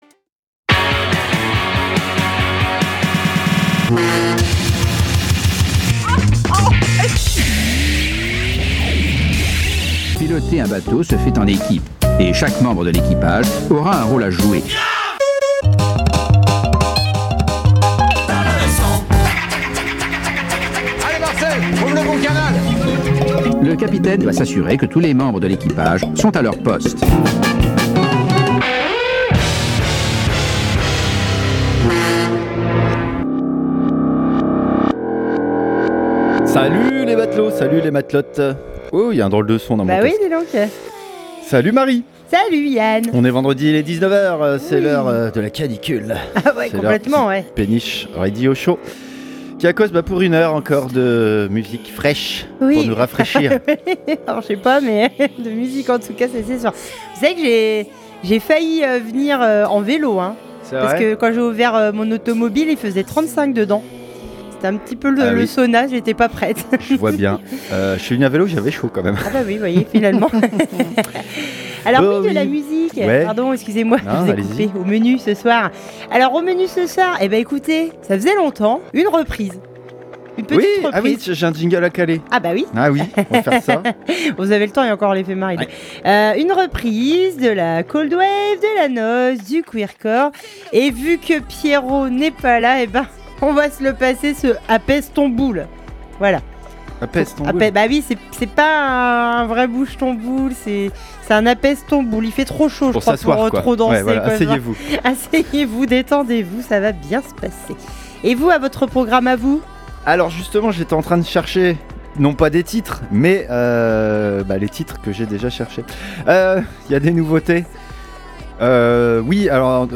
🎧 Péniche 14x26 - Péniche radio show